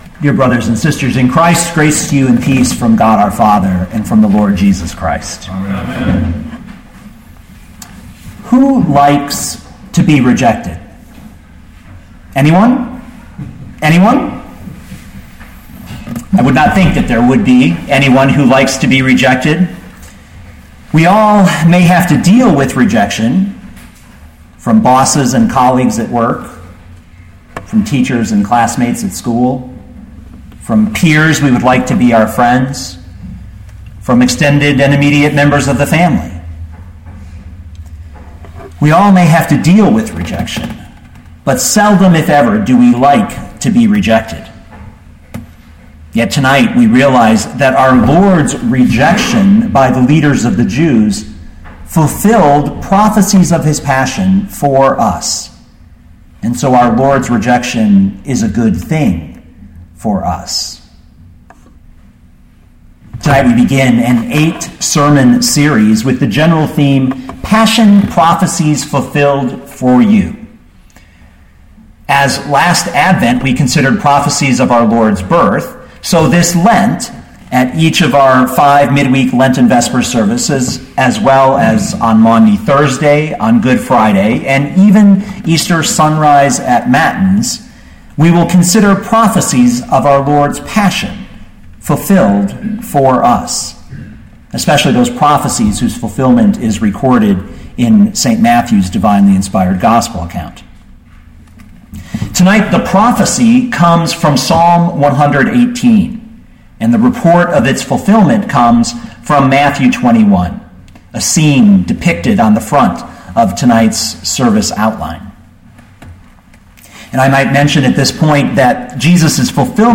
Mt 21:33-46 Listen to the sermon with the player below, or, download the audio.